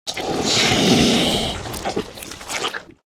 eat_2.ogg